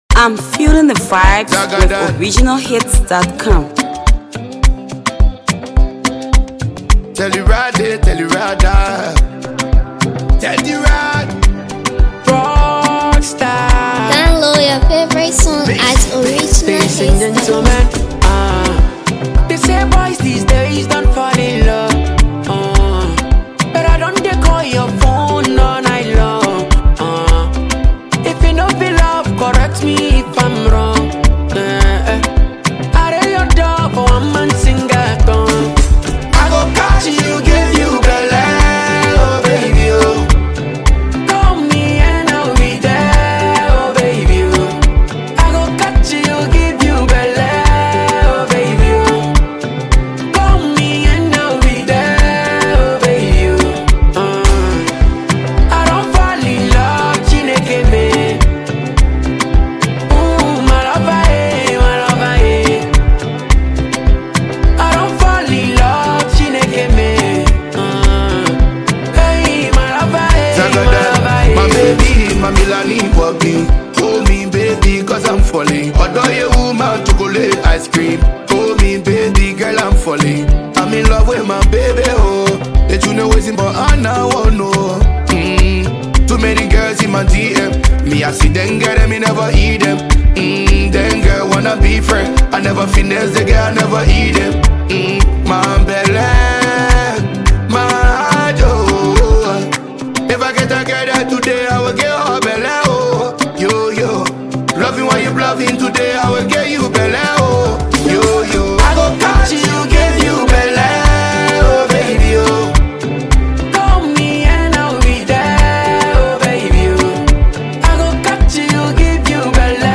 is a smooth, catchy melody